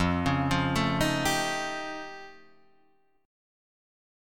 F Augmented 9th